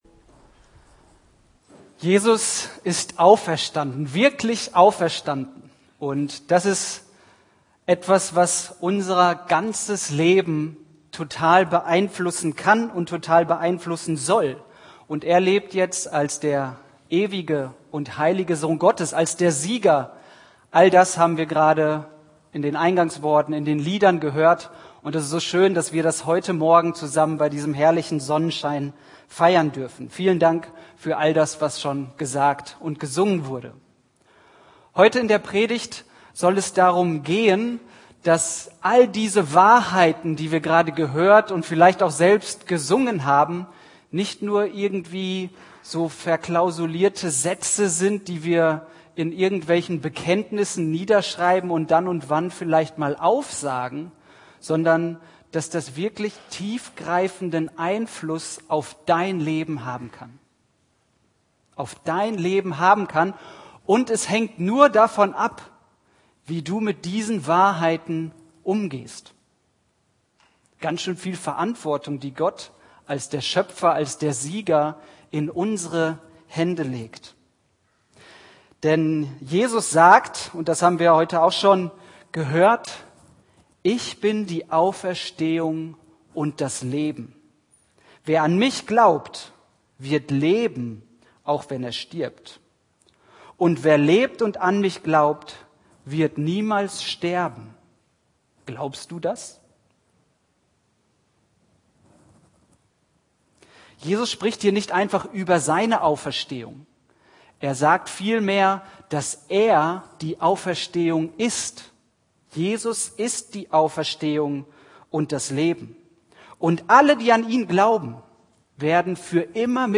Predigt Evangelien